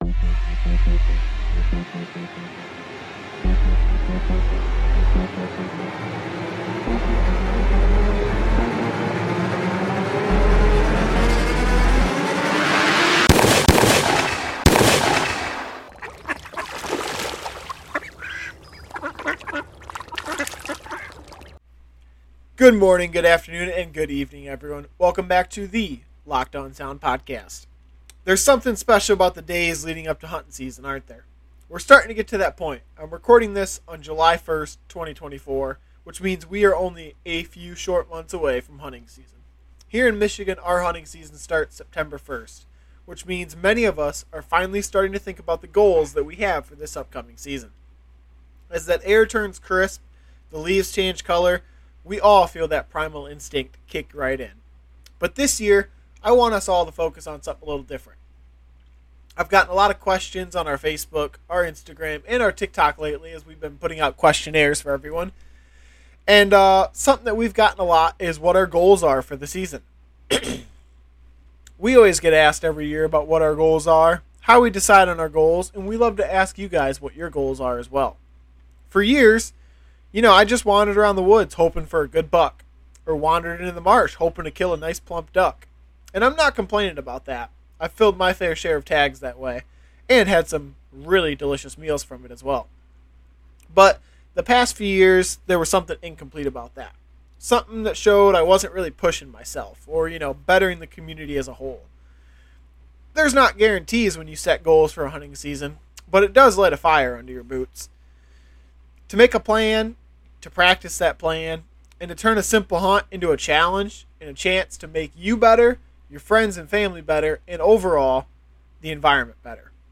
An outdoorsman's podcast, by outdoorsman. Locked On Sound Podcast Presents itself in all topics circling around hunting, angling, and anything else brought up throughout the laid-back conversation.